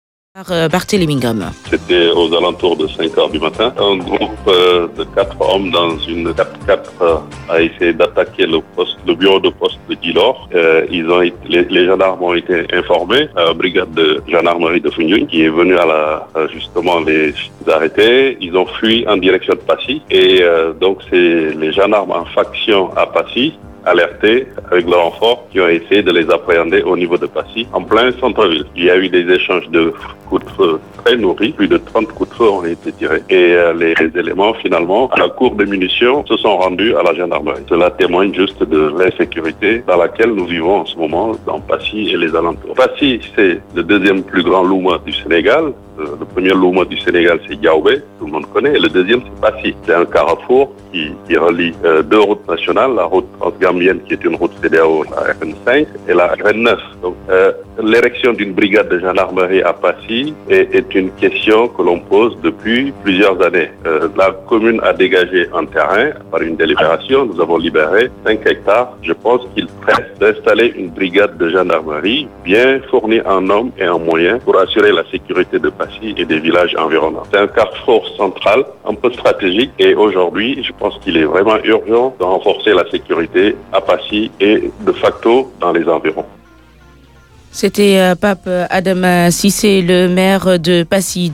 Le maire de Passy, Pape Adama Cissé a alerté sur l'insécurité grandissante qui règne dans cette commune et appelé les autorités à ériger un poste de gendarmerie pour protéger les biens et personnes. Écoutez son intervention sur les ondes de la Rfm !